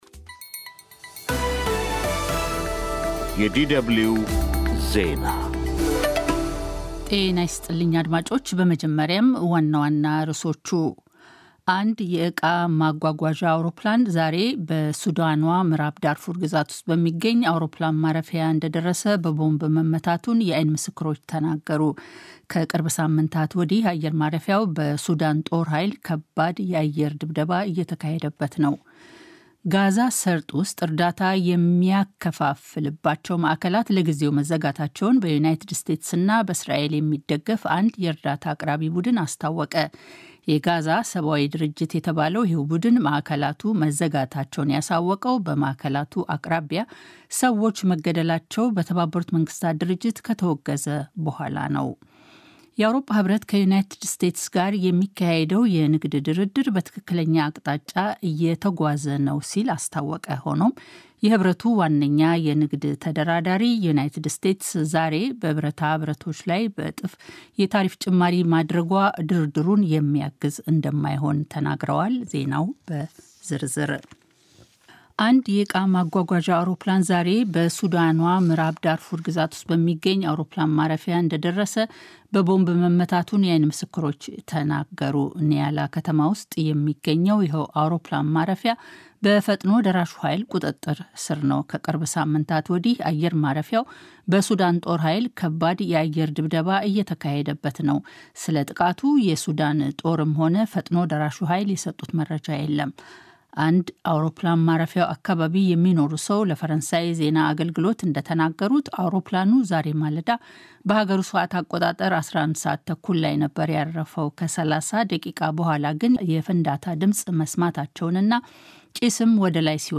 Daily News